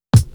WU_BD_075.wav